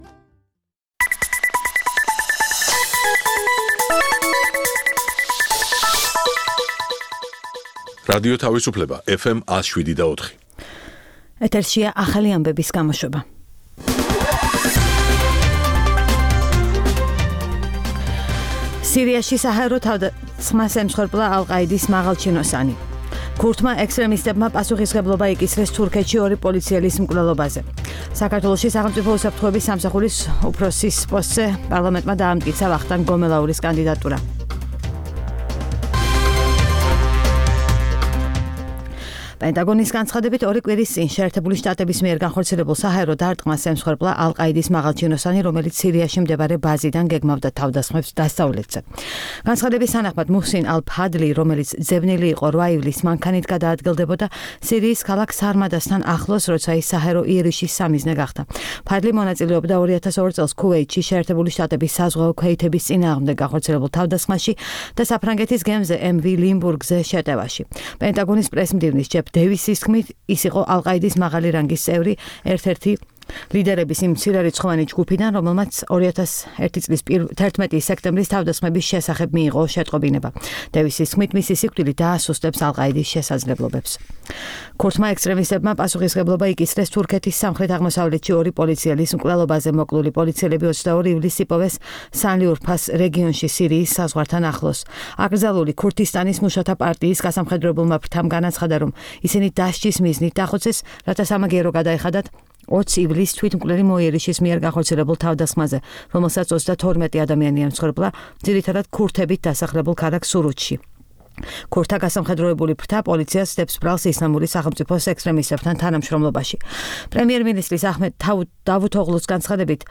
საუბარი თინა ხიდაშელთან